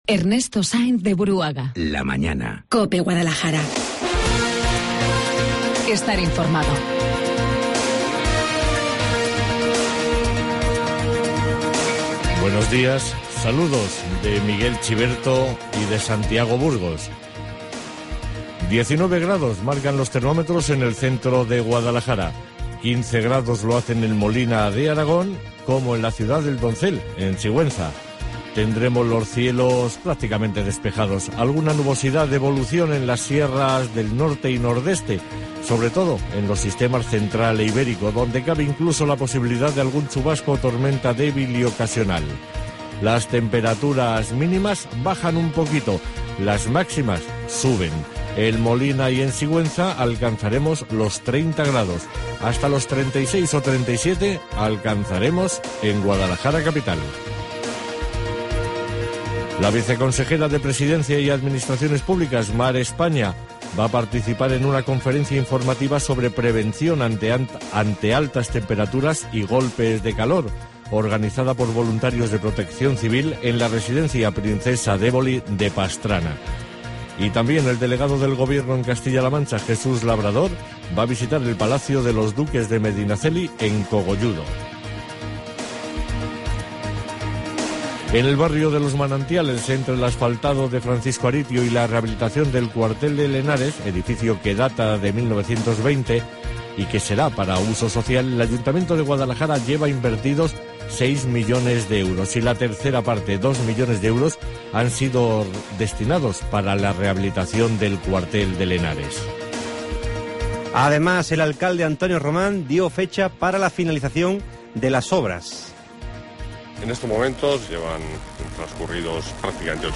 Informativo Guadalajara 14 DE AGOSTO